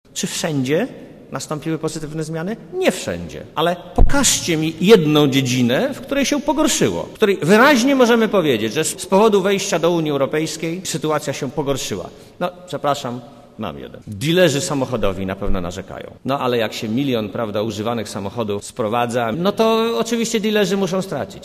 Mówi premier Marek Belka Korzyści z naszego bycia w Unii będzie więcej jeśli usuniemy bariery prawno-urzędnicze, jakie pojawiają się przy pozyskiwaniu funduszy europejskich – dodaje premier Belka.